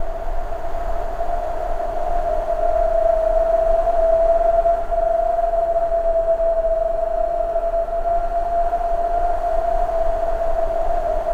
WIND HOWL1.wav